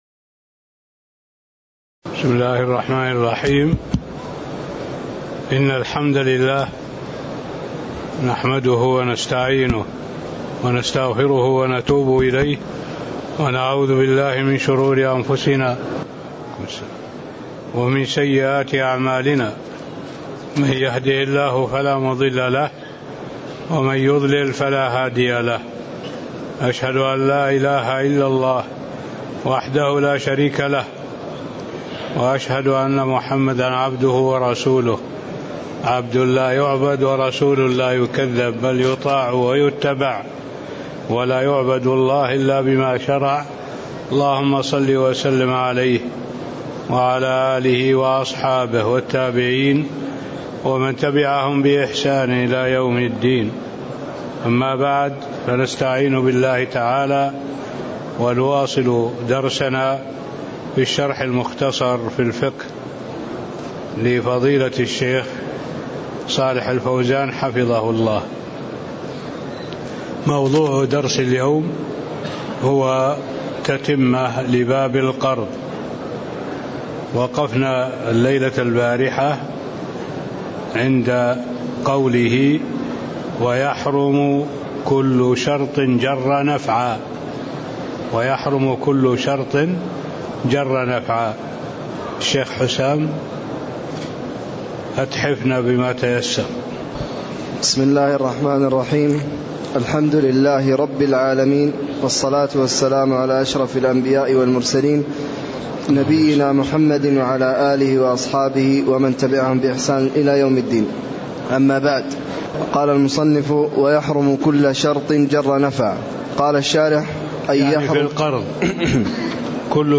تاريخ النشر ١٩ ربيع الأول ١٤٣٥ هـ المكان: المسجد النبوي الشيخ